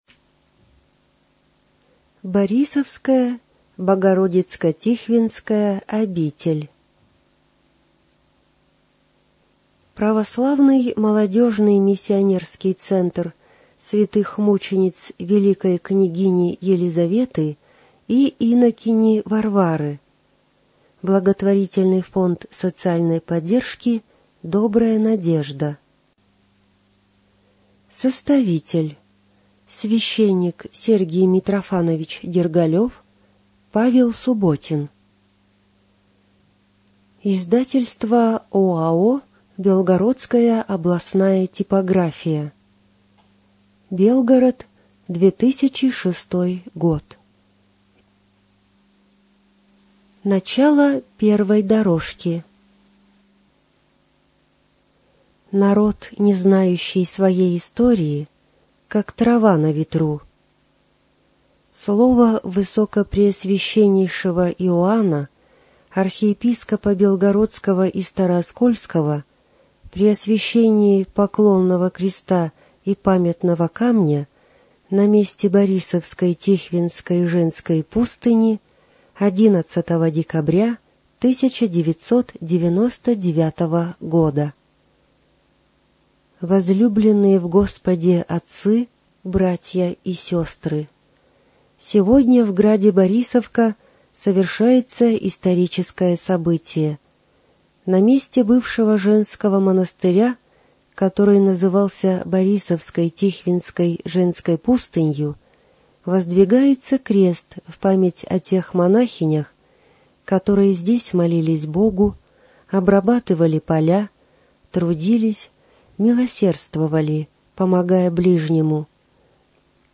ЖанрАудиоэкскурсии и краеведение
Студия звукозаписиБелгородская областная библиотека для слепых имени Василия Яковлевича Ерошенко